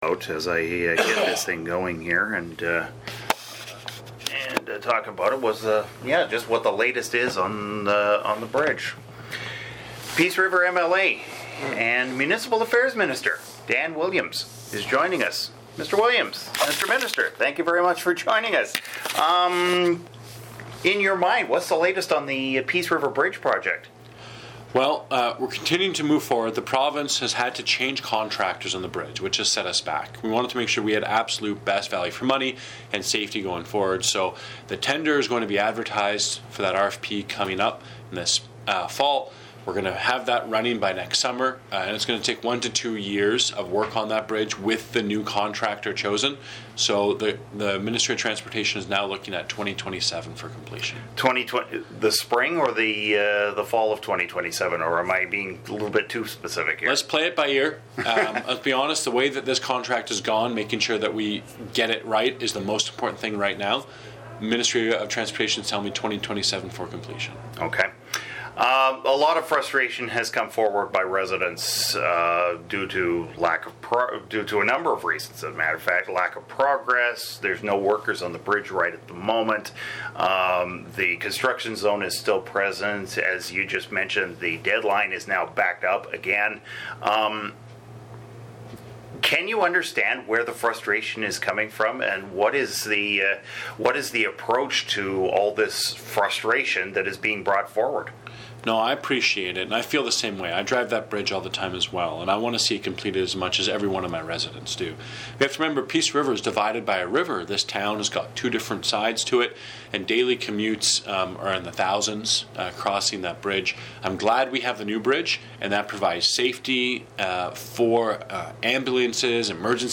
dan-williams-bridge.mp3